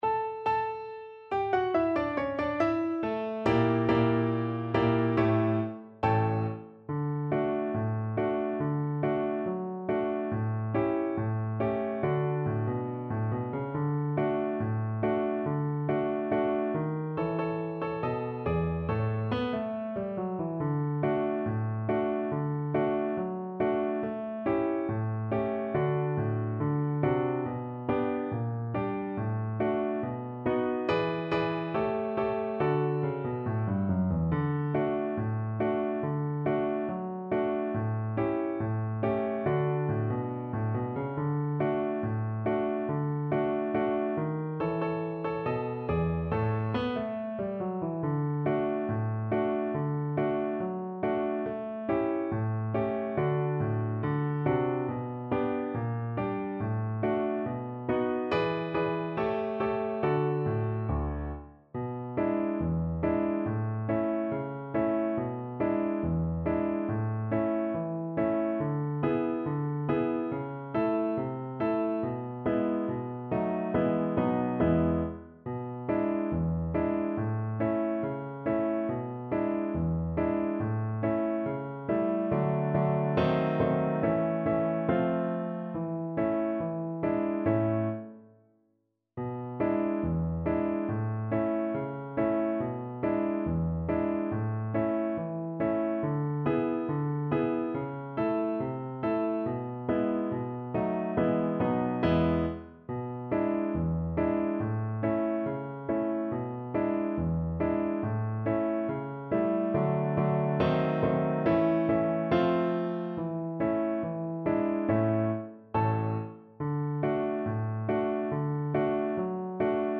2/4 (View more 2/4 Music)
Not fast Not fast. = 70
Jazz (View more Jazz Cello Music)